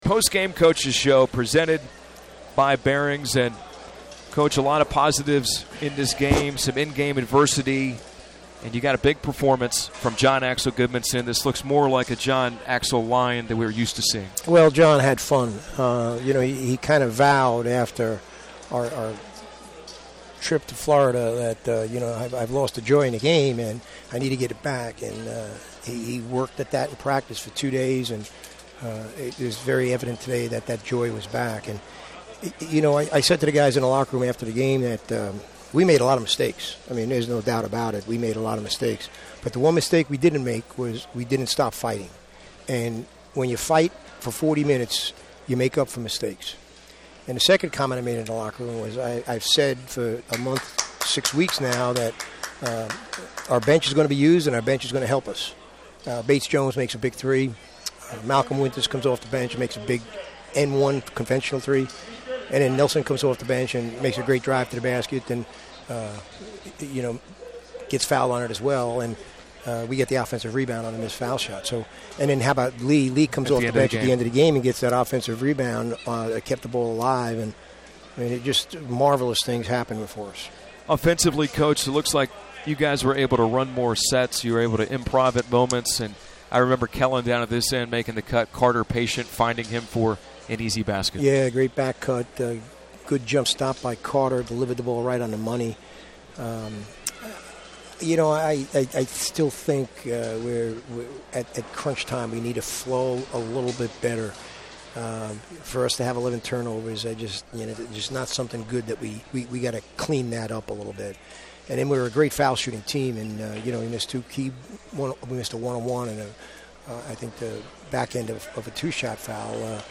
McKillop Postgame Interview
McKillop Postgame NE.mp3